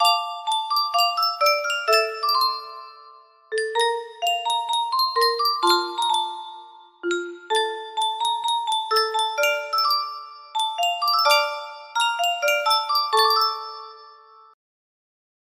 Yunsheng Music Box - Unknown Tune 1134 music box melody
Full range 60